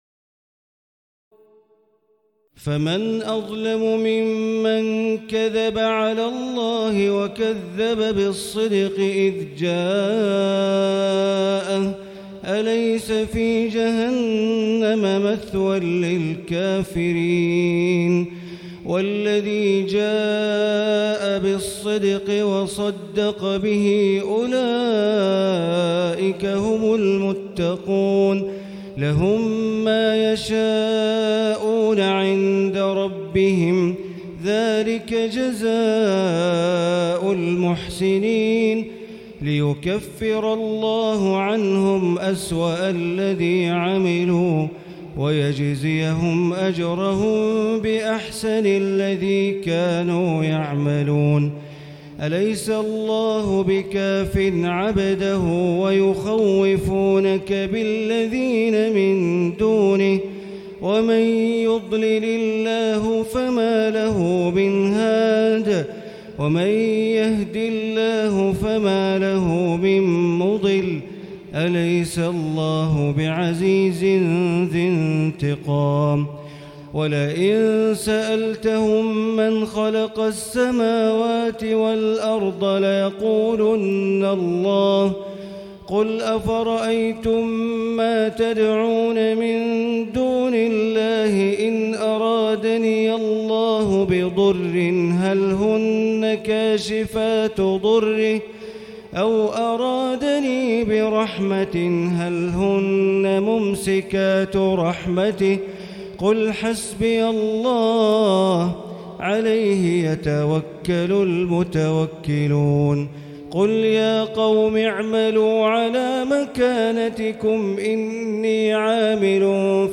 تراويح ليلة 23 رمضان 1439هـ من سور الزمر (32-75) و غافر (1-46) Taraweeh 23 st night Ramadan 1439H from Surah Az-Zumar and Ghaafir > تراويح الحرم المكي عام 1439 🕋 > التراويح - تلاوات الحرمين